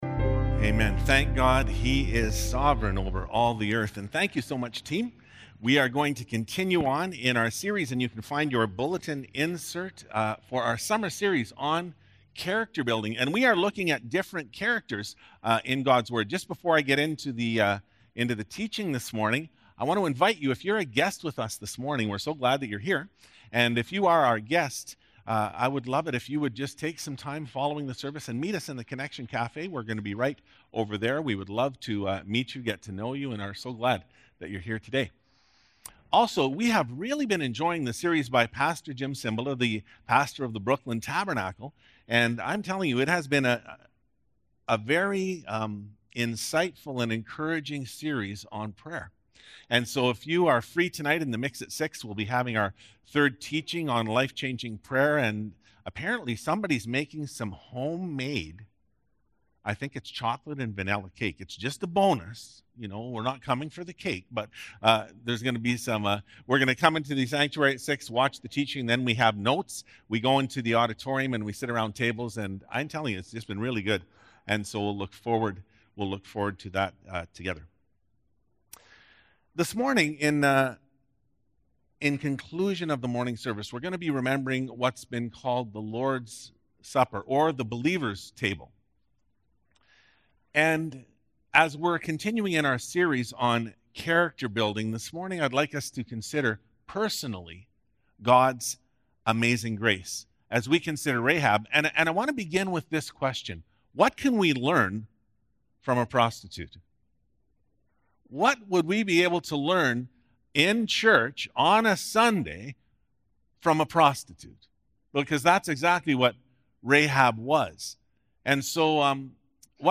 Sermons | Southside Pentecostal Assembly